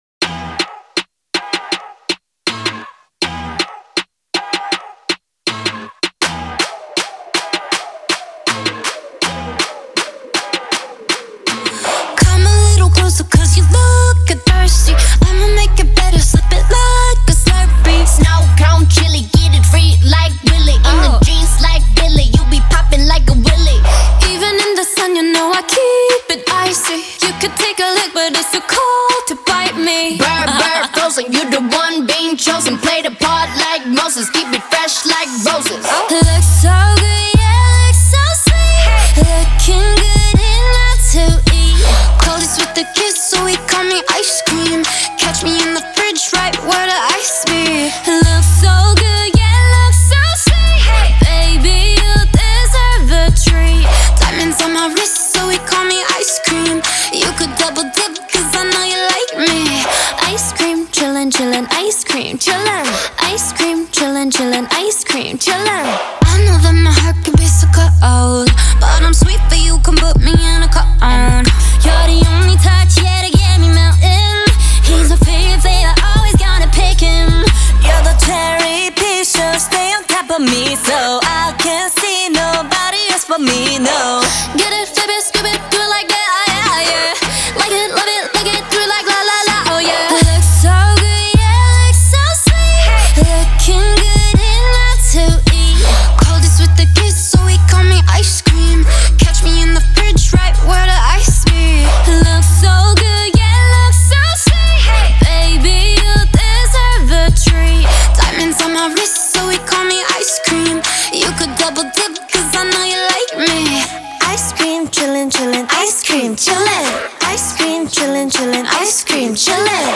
это яркая и игривая поп-песня в жанре K-pop